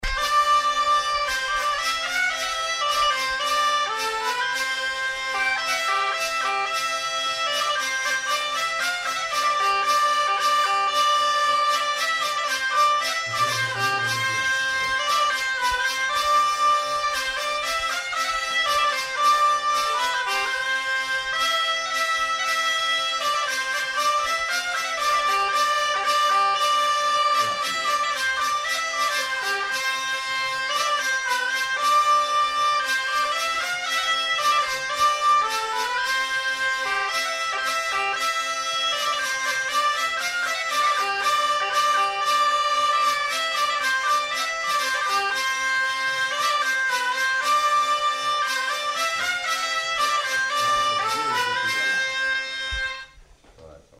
Scottish
Lieu : Pyrénées-Atlantiques
Genre : morceau instrumental
Instrument de musique : vielle à roue ; cabrette
Danse : scottish